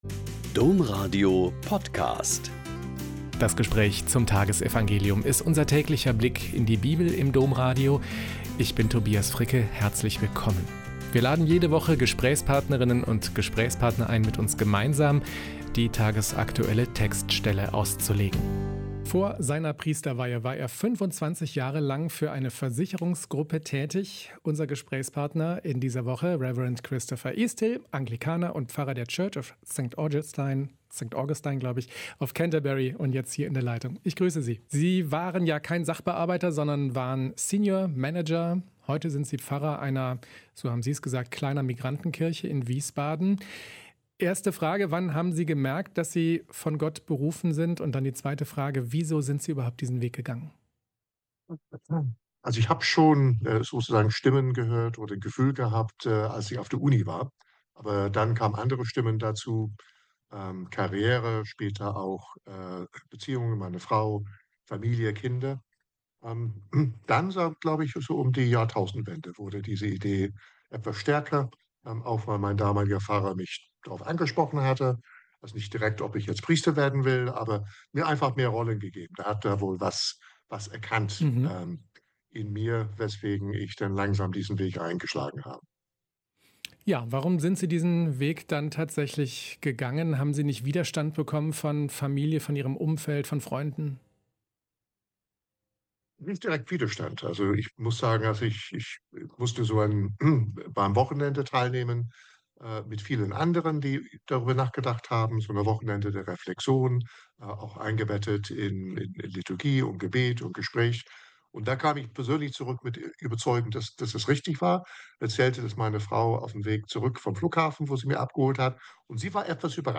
Joh 15,1-8 - Gespräch